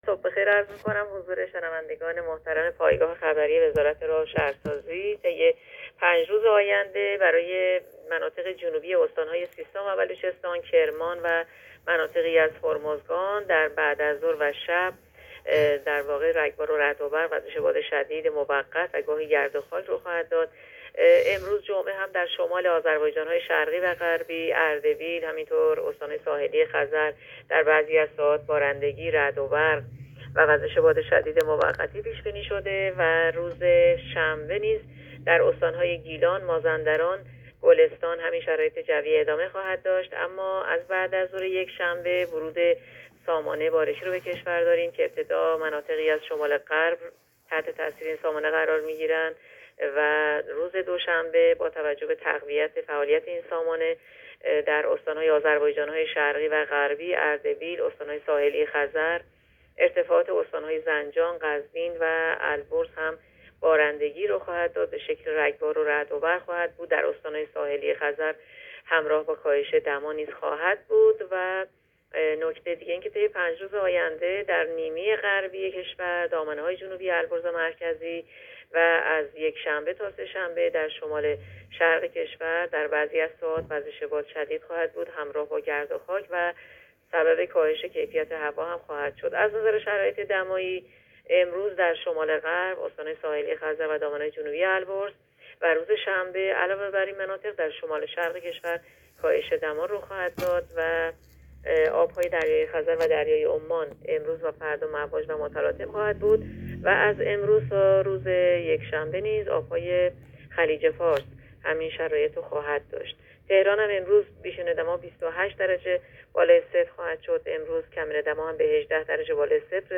گزارش رادیو اینترنتی پایگاه‌ خبری از آخرین وضعیت آب‌وهوای ۱۸ مهر؛